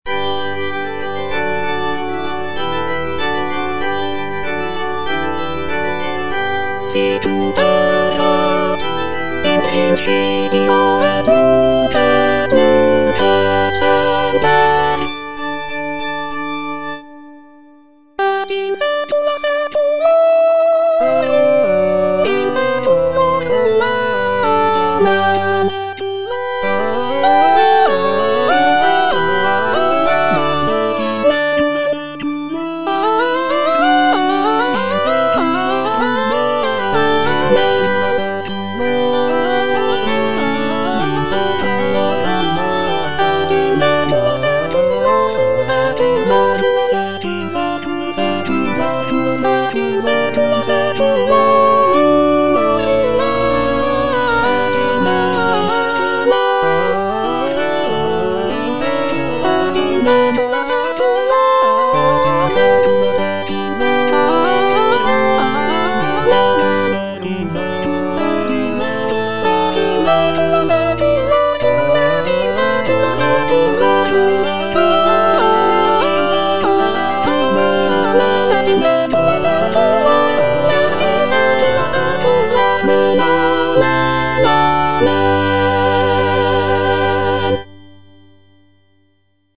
B. GALUPPI - MAGNIFICAT Sicut erat in principio - Soprano (Emphasised voice and other voices) Ads stop: auto-stop
"Magnificat" by Baldassare Galuppi is a sacred choral work based on the biblical text of the Virgin Mary's song of praise from the Gospel of Luke.
The work features intricate vocal lines, rich harmonies, and dynamic contrasts, creating a powerful and moving musical experience for both performers and listeners.